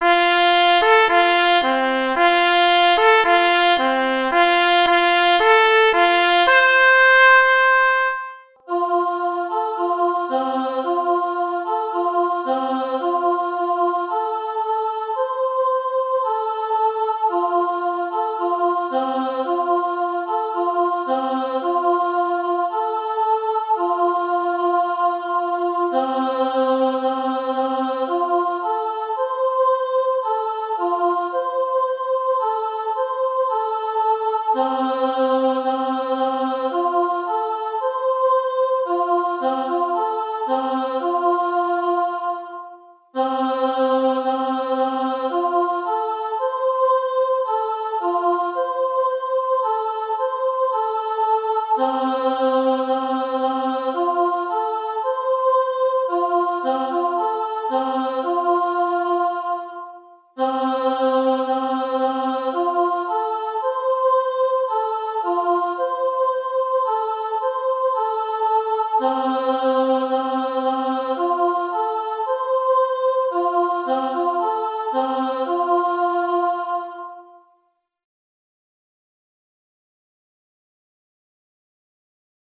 Gatunek utworu: hejnał
śpiew